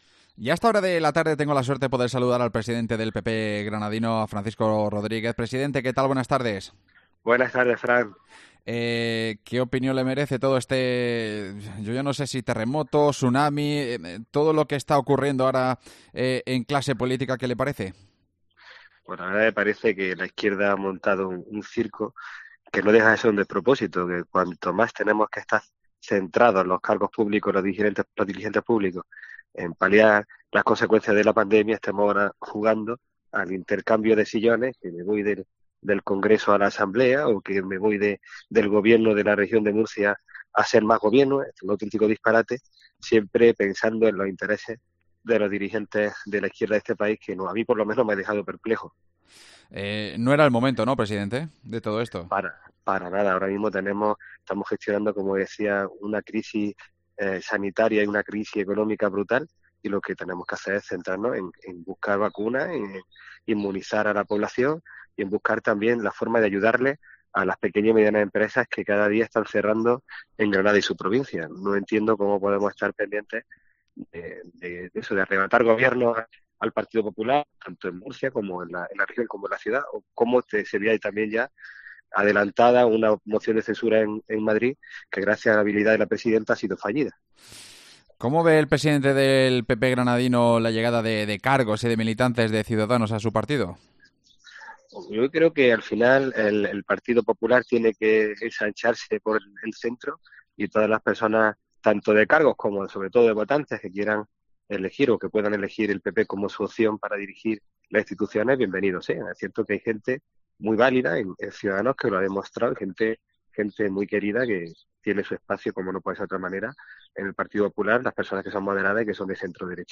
El presidente del PP granadino habla en COPE sobre el terremoto político en España